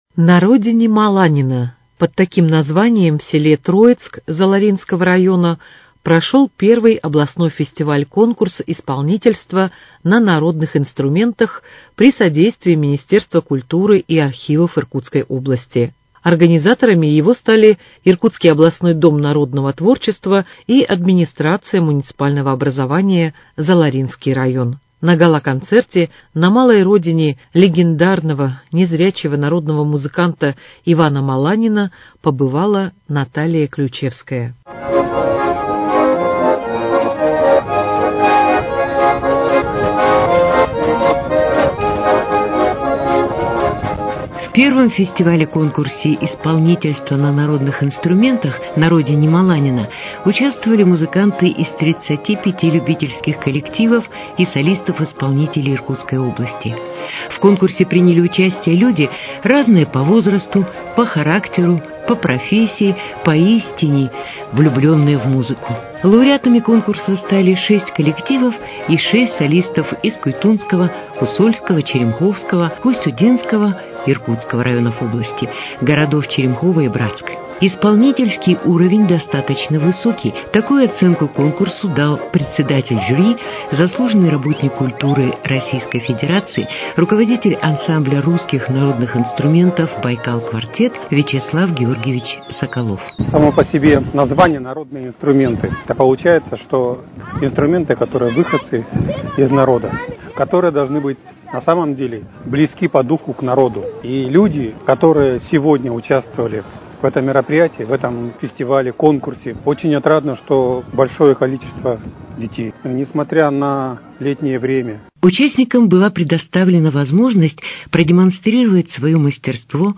Репортаж о первом фестивале-конкурсе памяти слепого баяниста «На родине Маланина»